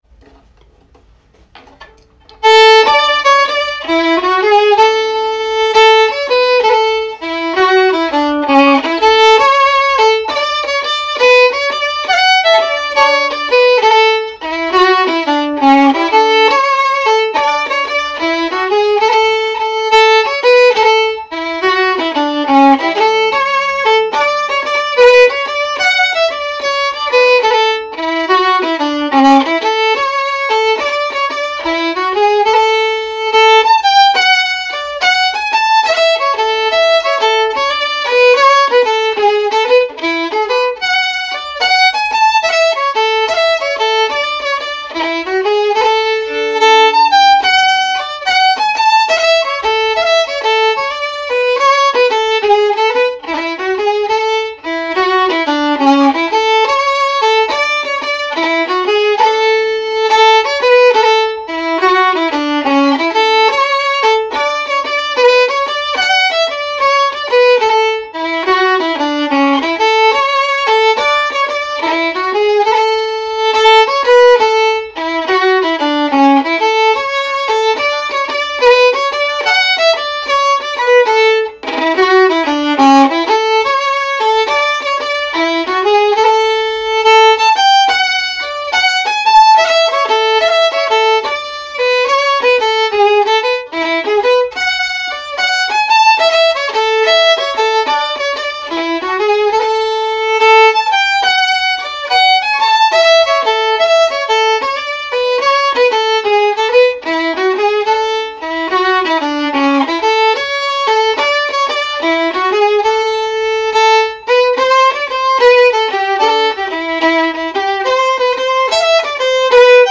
It promotes traditional Cape Breton style music through fiddle, guitar, piano, singers, step dancers, and lovers of Cape Breton Fiddle Music.